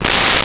Tear Paper 06
Tear Paper 06.wav